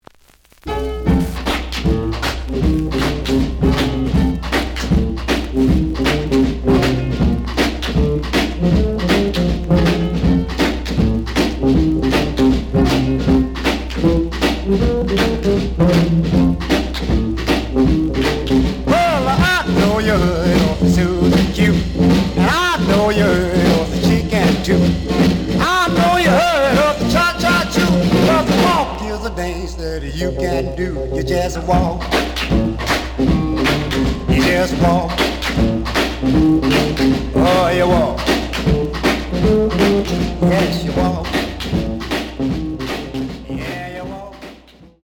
The audio sample is recorded from the actual item.
●Genre: Rhythm And Blues / Rock 'n' Roll
Some noise on beggining of A side.)